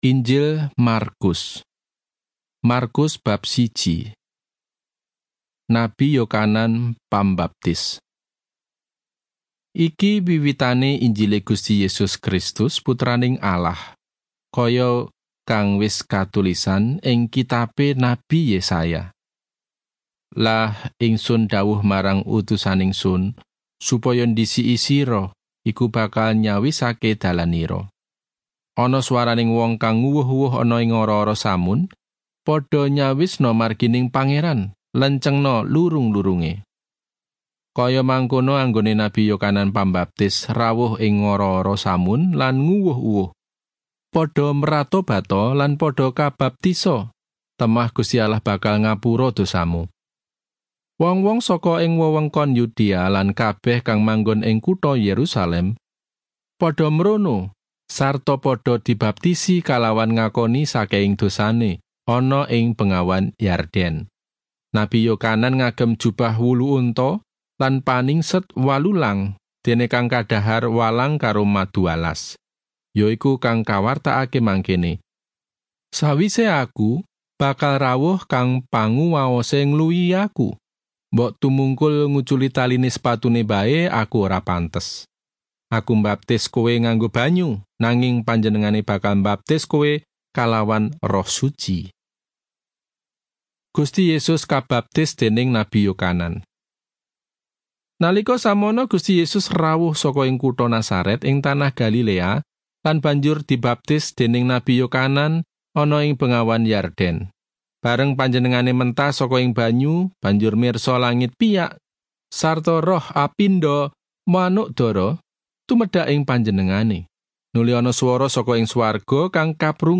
• Word for word narration
• Voice only Bible reading
javanese-bible-5848-mark-1.mp3